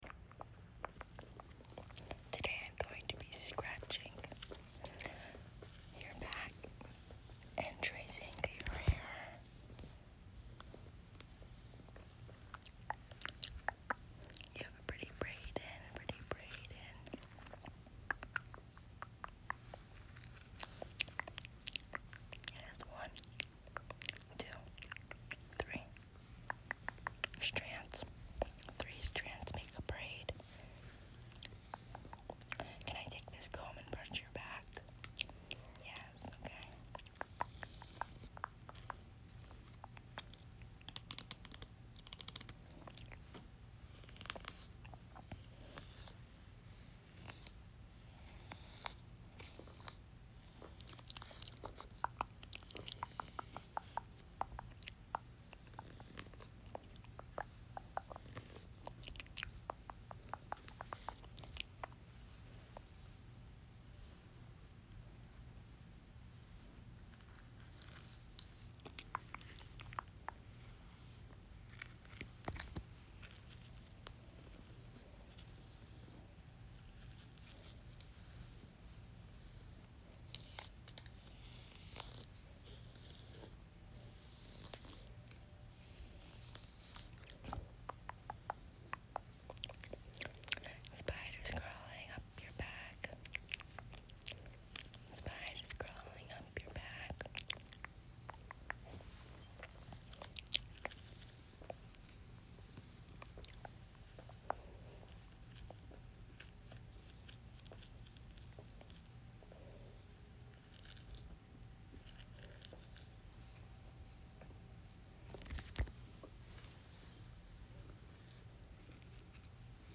Back scratching and hair playing